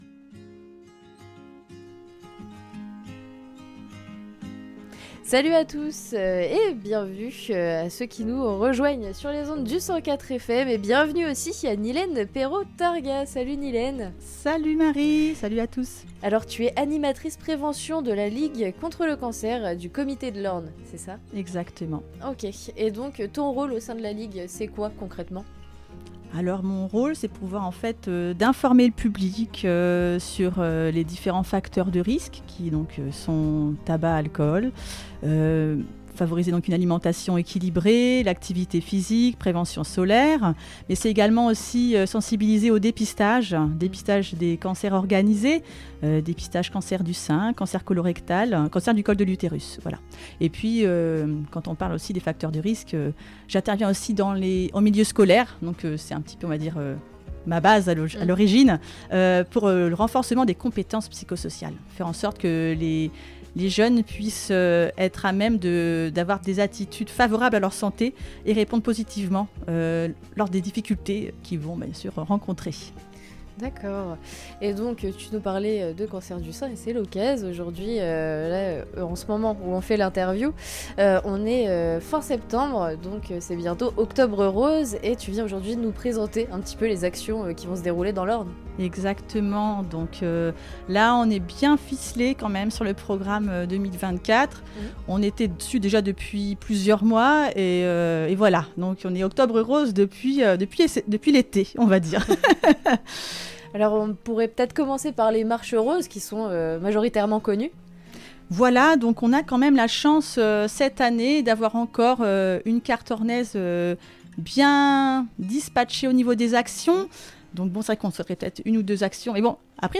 Interviews RCDF